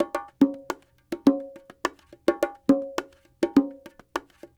44 Bongo 14.wav